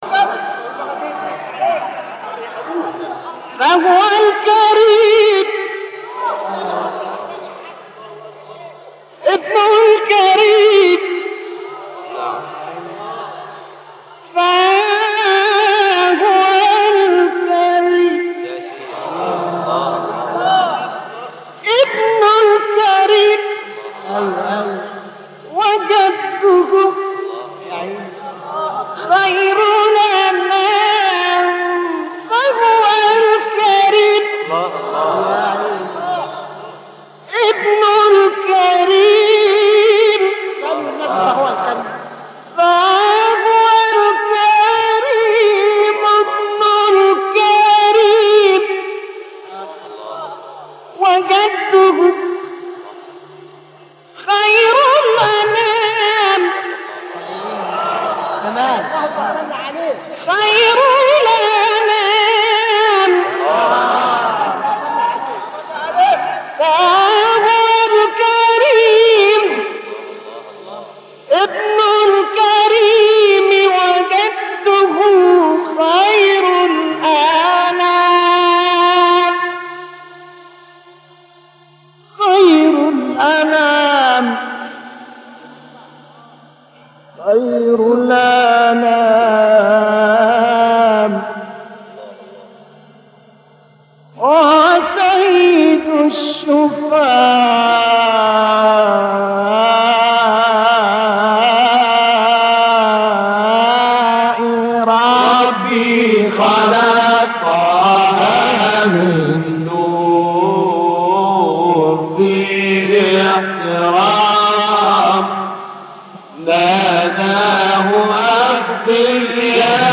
مقام صبا ( تواشيح ) ـ ربي خلق طه من نور ـ طه الفشندي - لحفظ الملف في مجلد خاص اضغط بالزر الأيمن هنا ثم اختر (حفظ الهدف باسم - Save Target As) واختر المكان المناسب